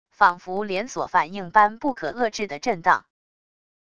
仿佛连锁反应般不可遏制的震荡wav音频